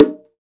taiko-soft-hitnormal.ogg